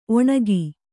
♪ oṇagi